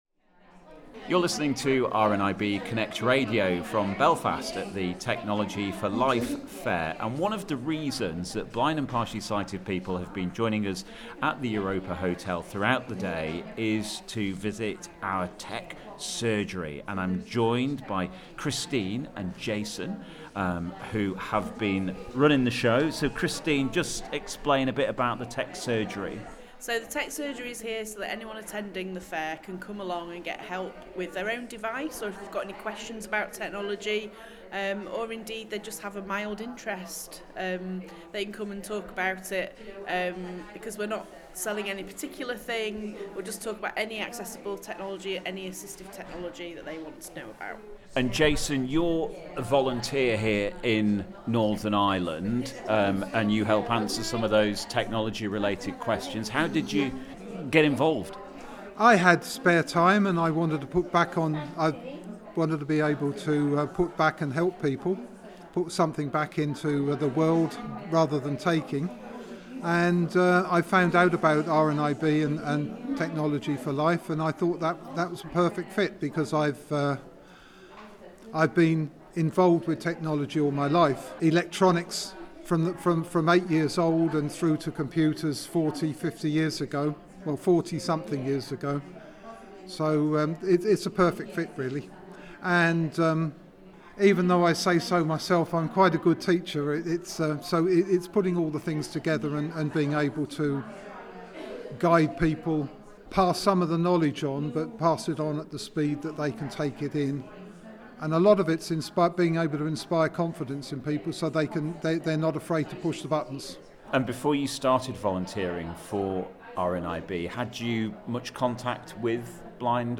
RNIB recently held the ‘Technology For Life Fair’ in Belfast, showcasing assistive technology products in Northern Ireland.